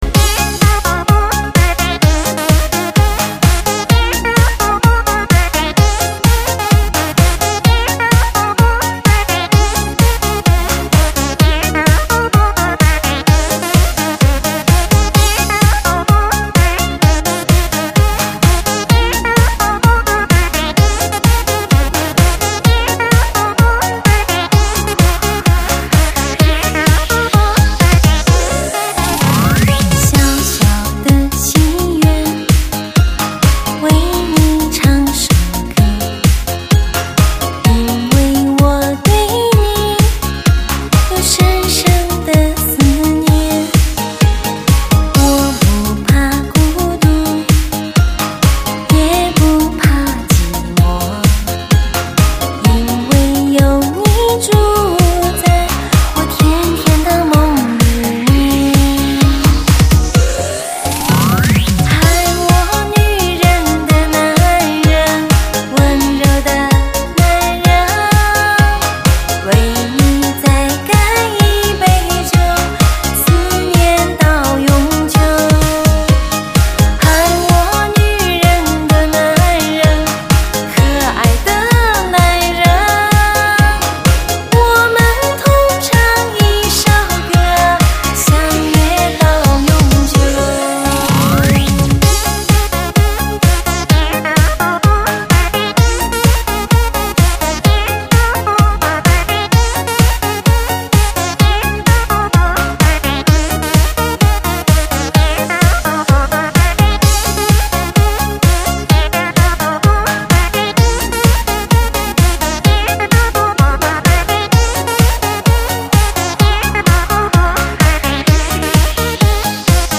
震撼人心的音乐HOT DISCO